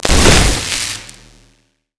electric_surge_blast_02.wav